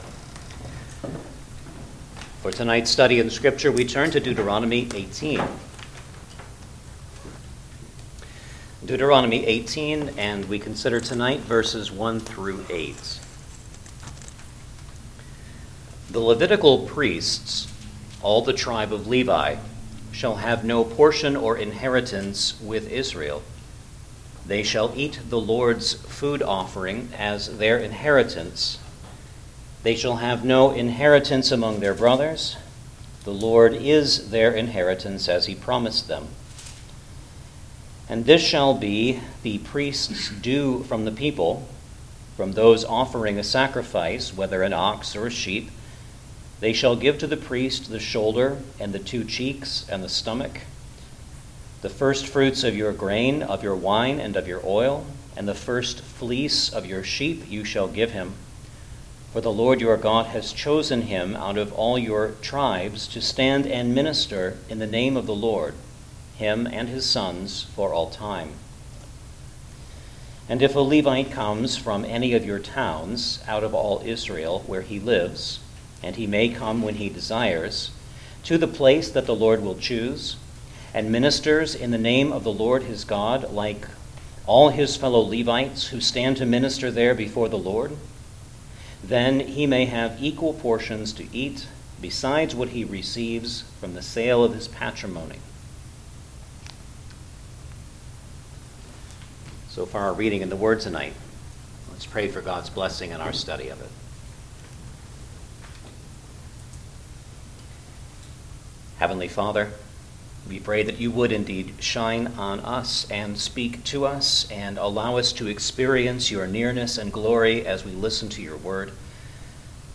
Deuteronomy Passage: Deuteronomy 18:1-8 Service Type: Sunday Evening Service Download the order of worship here .